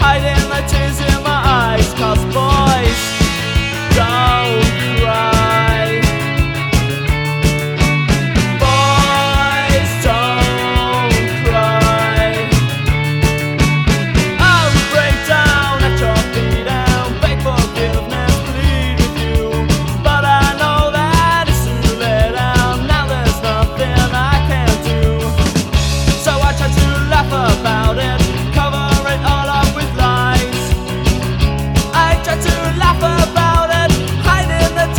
Жанр: Поп / Альтернатива